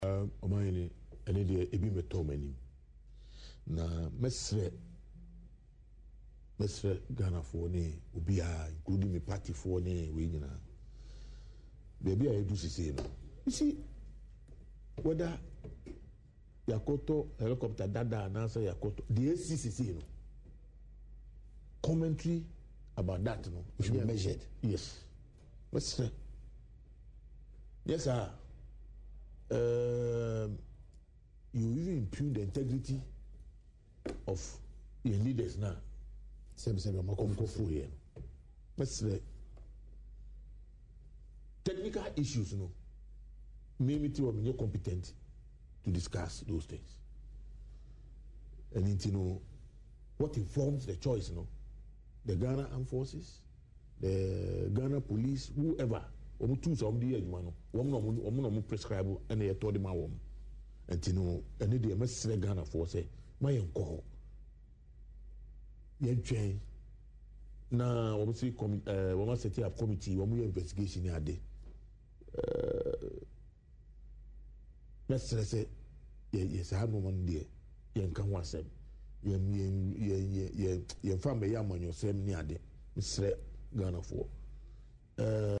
Speaking on Adom TV’s Badwam, the Lower Manya Krobo MP acknowledged that the state of the police helicopters had not been brought to his attention.